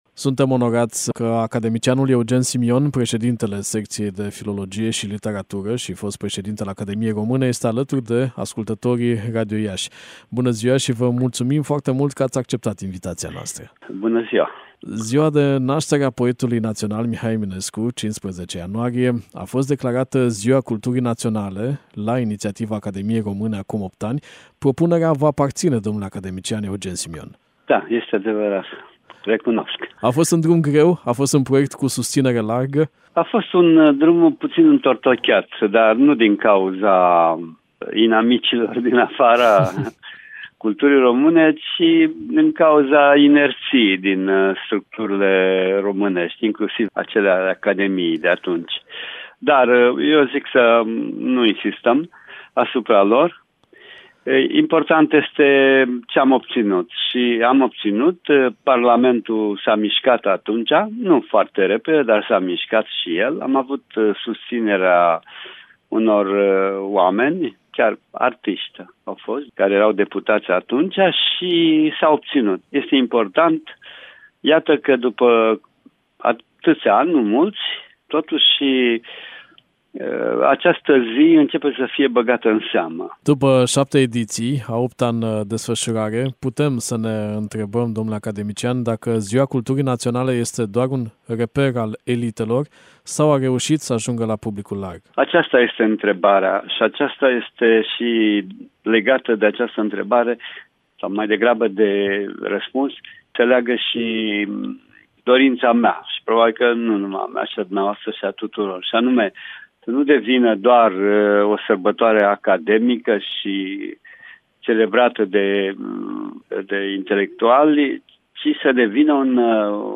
Ziua Culturii Naționale – interviu cu academicianul Eugen Simion